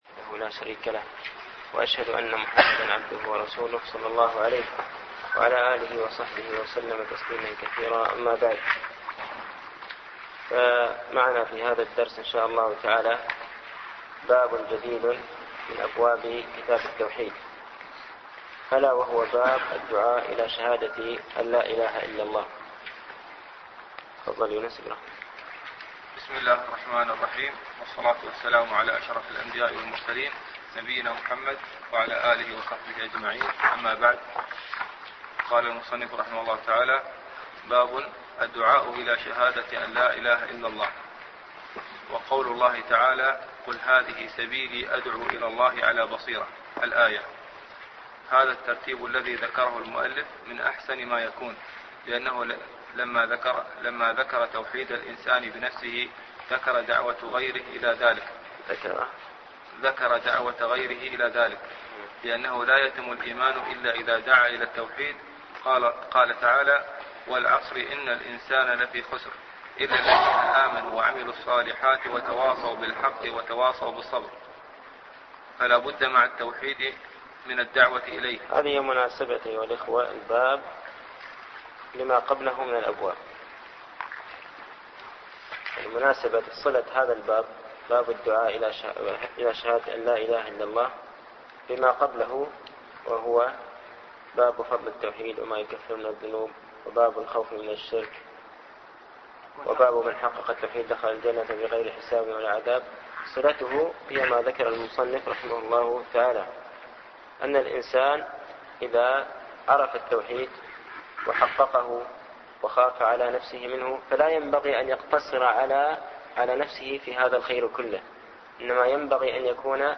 التعليق على القول المفيد على كتاب التوحيد - الدرس الرابع عشر